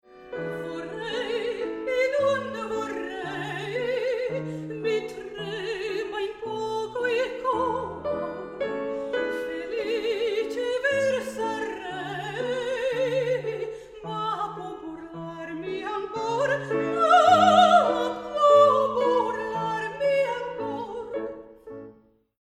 sopraan voor klassieke en lichte muziek